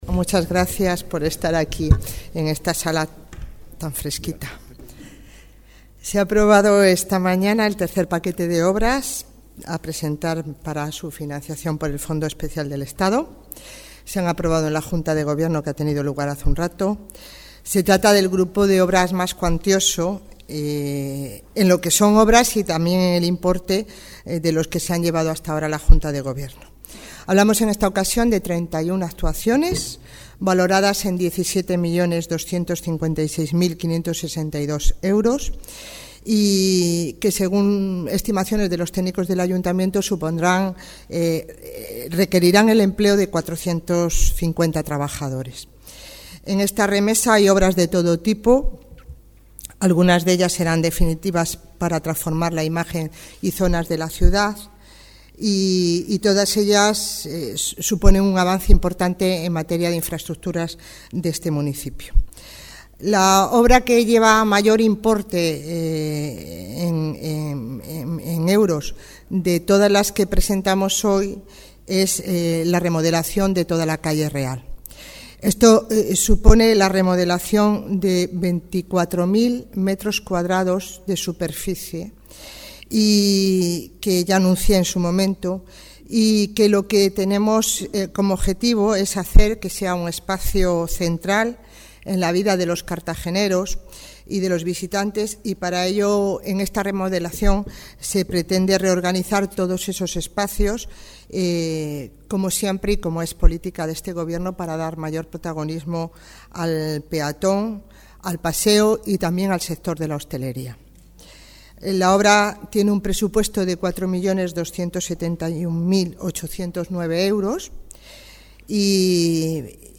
Audio: 2009-01-16 Declaraciones alcaldesa sobre el nuevo paquete de proyectos para el fondo Estatal (MP3 - 20,90 MB)